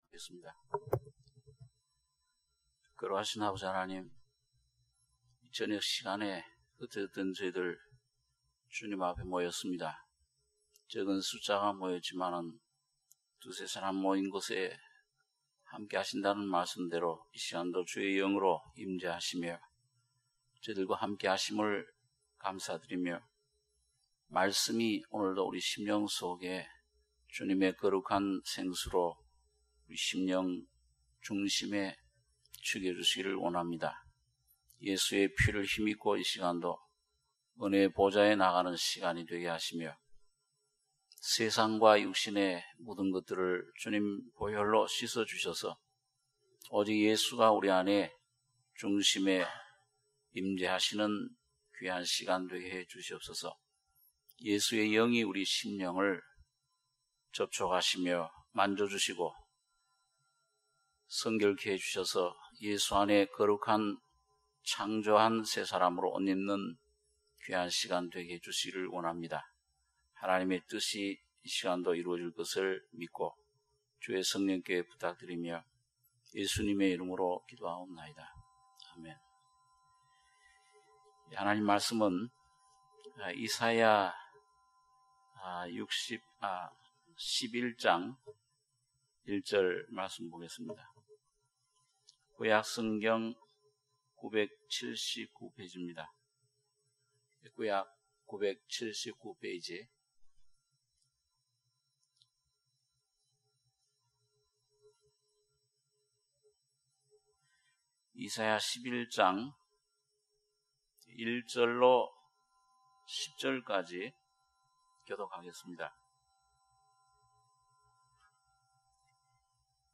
수요예배 - 이사야 11장 1~10절